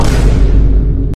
Vine Boom Sound
VINE-BOOM-SOUND-Sound-Effects-Download-.mp3